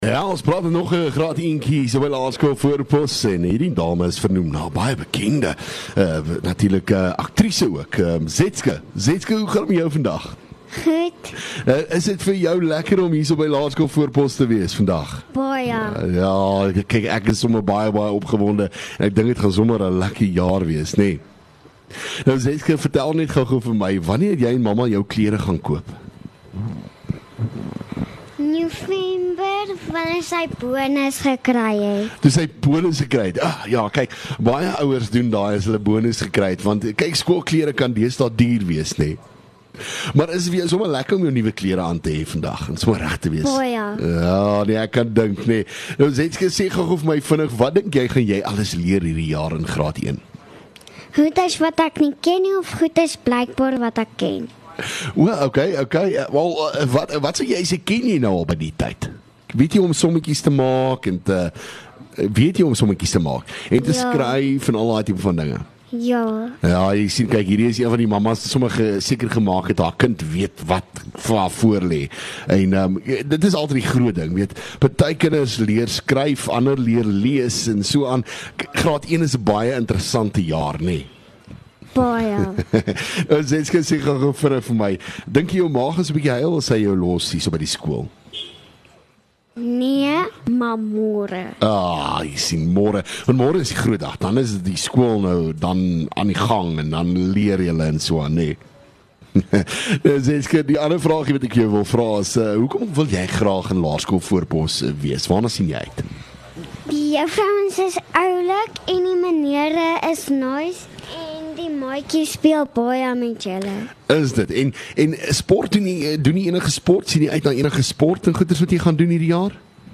LEKKER FM | Onderhoude 14 Jan Laerskool Voorpos